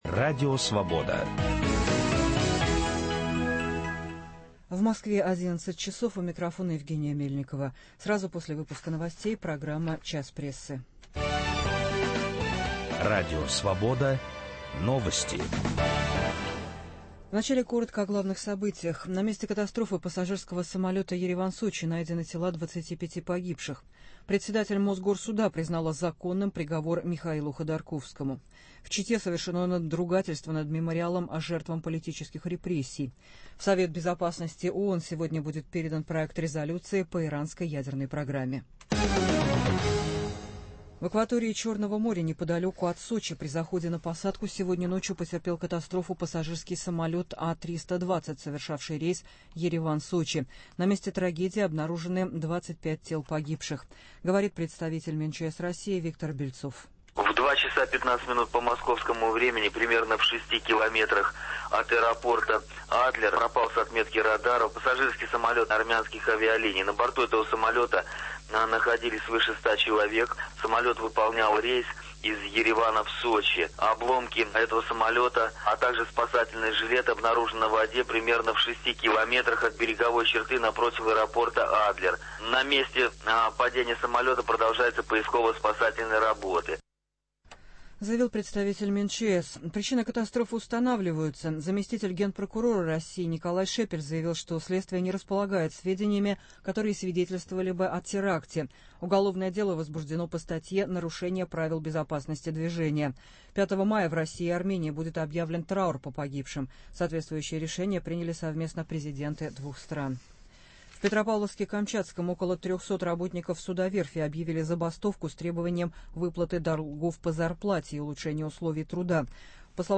Поводы для прямого президентского вмешательства в ситуацию и поводы для народных протестов, когда ситуация не разрешается. В студии автор цикла "Писем президенту" в газете "Московский комсомолец" Александр Минкин.